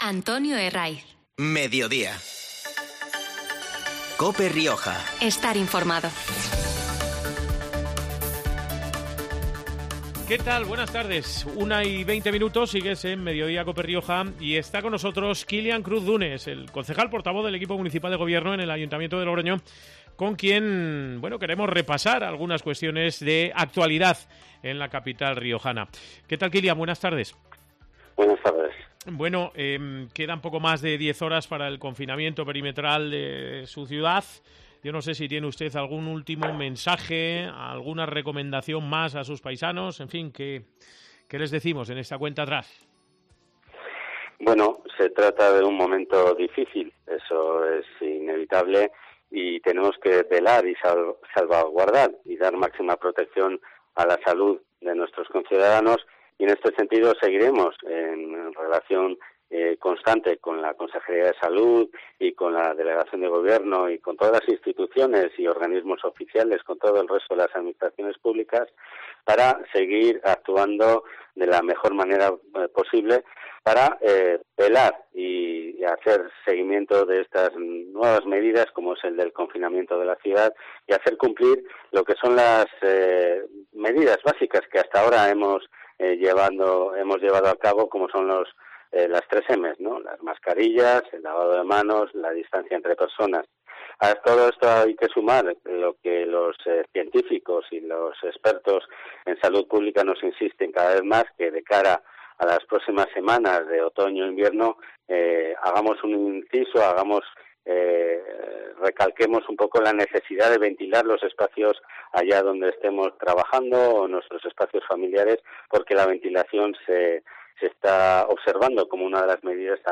El portavoz del equipo municipal de Gobierno en el Ayuntamiento de Logroño analiza la actualidad en la capital riojana, que será confinada...